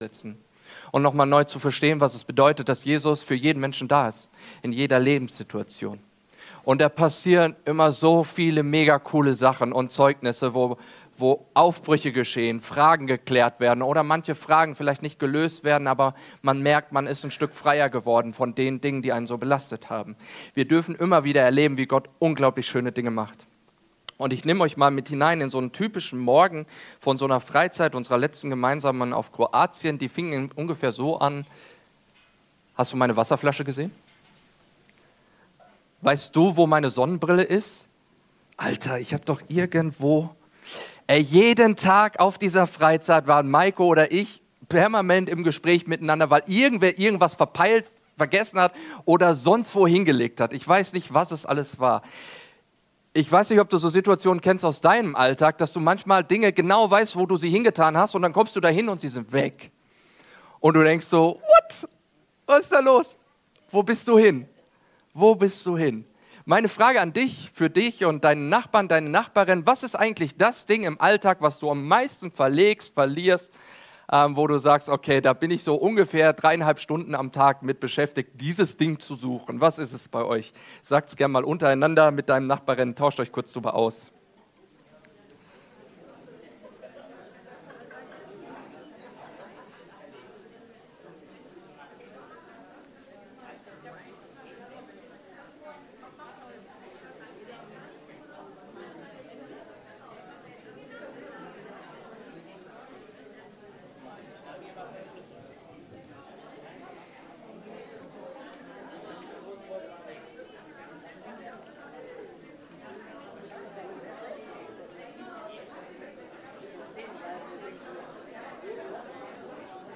Nimm dir einen Moment und rede mit Jesus Und gebe es Jesus Predigt vom 16. November 2025 im 11 Uhr Gottesdienst der freien evangelischen Gemeinde (FeG) Mönchengladbach, unterwegs.